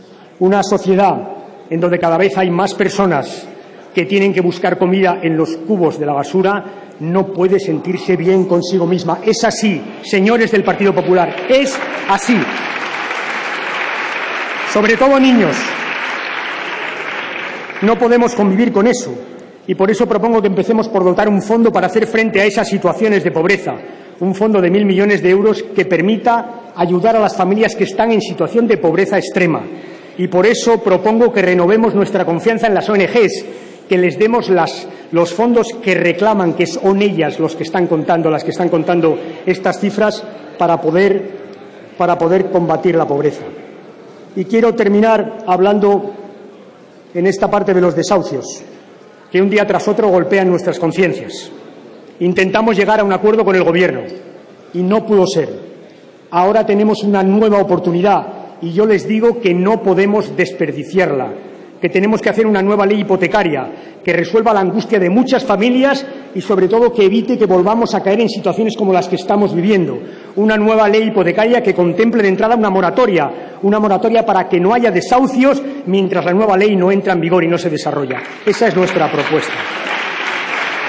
Debate del Estado de la Nación 20/02/2013